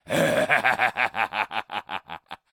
beyond/Assets/Sounds/Enemys/Male/laugch3.ogg at main
laugch3.ogg